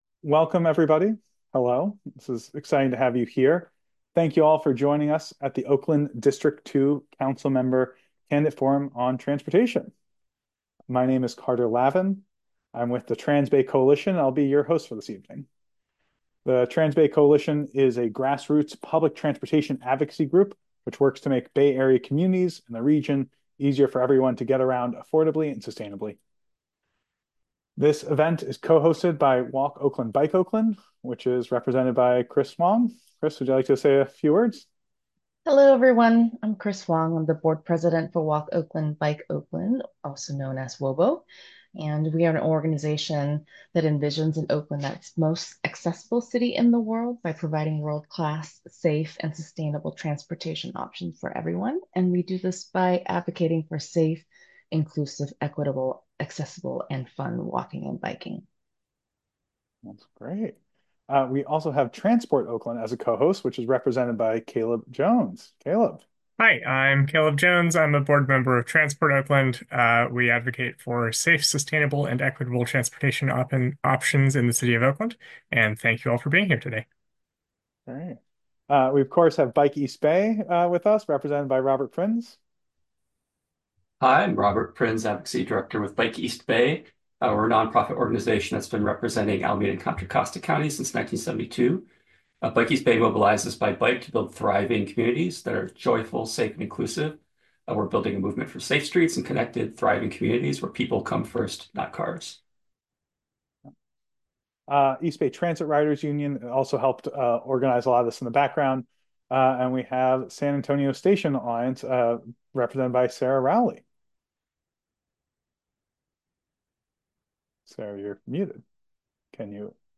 Walk Oakland Bike Oakland, Transport Oakland, Bike East Bay, East Bay Transit Riders Union, San Antonio Station Alliance, and the Transbay Coalition held a forum Thursday evening with four of the candidates to hear their views on issues near-and-dear to safe-and-liveable-streets advocates.
OaklandDistrict2DebateAudio.mp3